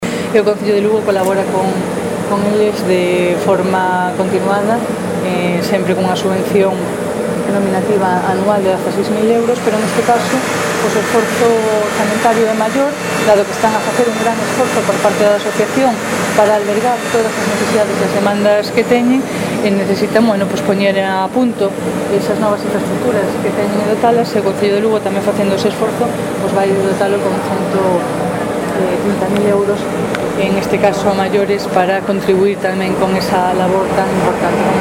Audio Declaracións da Alcaldesa durante a súa visita ás instalacións de ASPNAIS | Descargar mp3